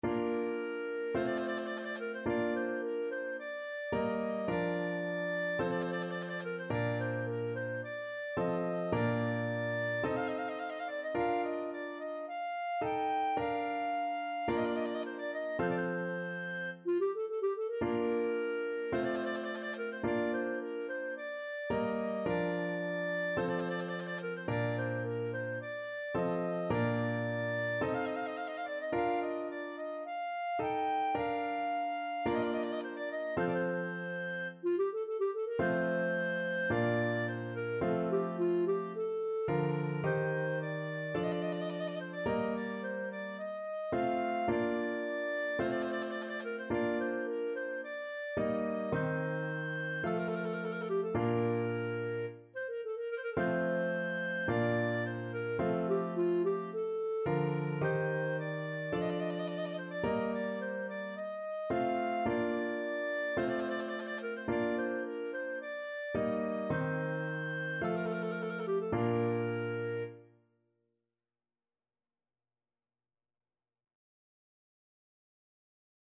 Clarinet
Bb major (Sounding Pitch) C major (Clarinet in Bb) (View more Bb major Music for Clarinet )
4/4 (View more 4/4 Music)
= 54 Slow
Classical (View more Classical Clarinet Music)